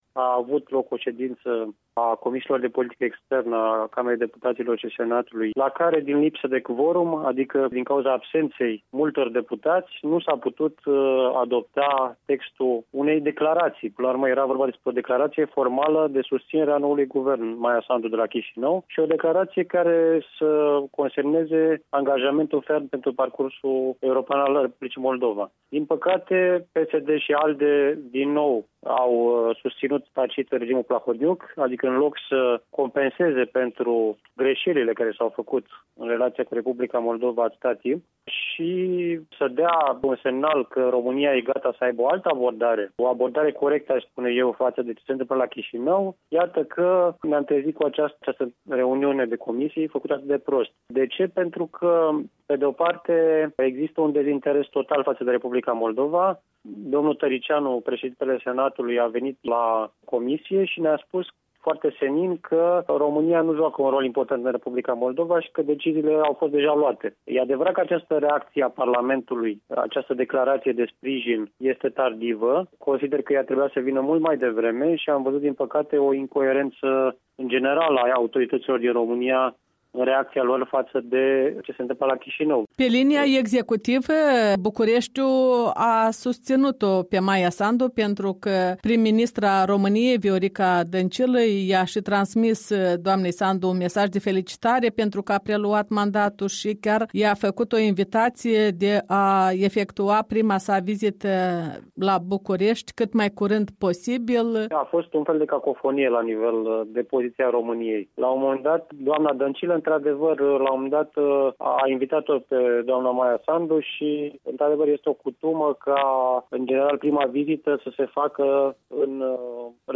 Un interviu cu parlamentarul român despre ecourile în România ale evenimentelor din R. Moldova.